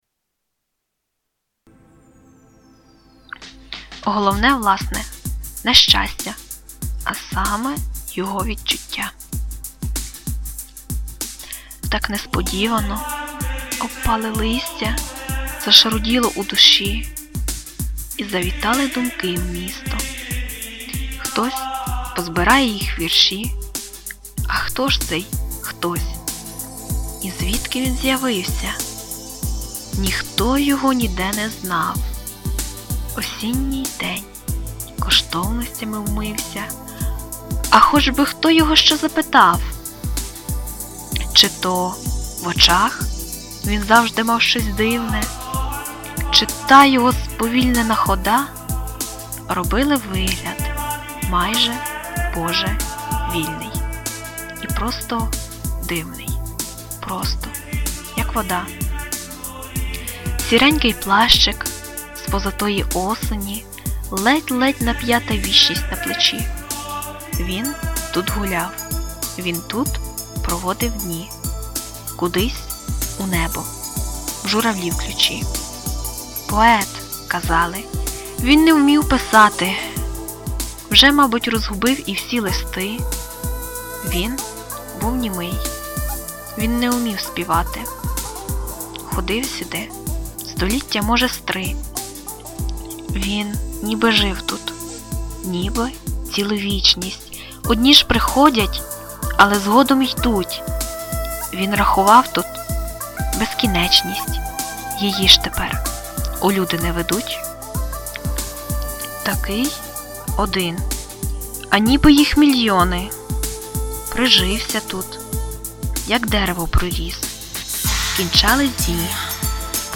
Рубрика: Поезія, Філософська лірика
дуже гарний голос)))
музика правда інколи перекриває..